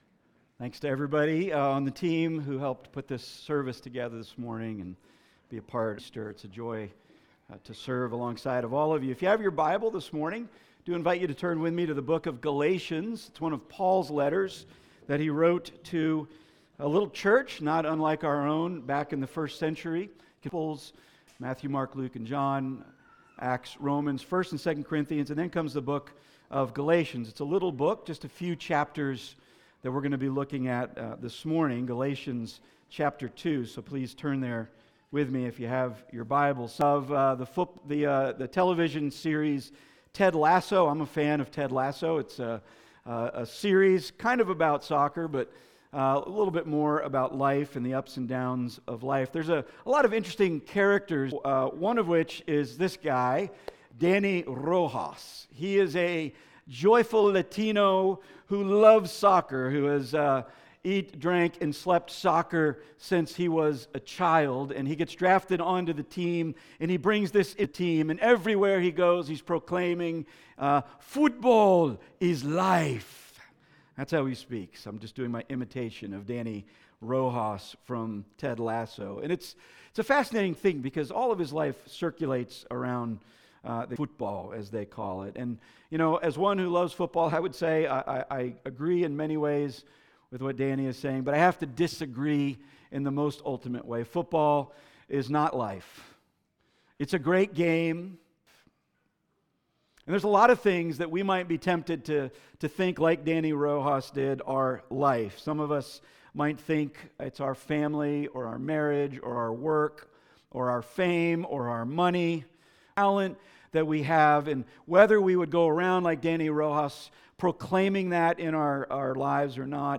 Passage: Galatians 2:19-21 Service Type: Weekly Sunday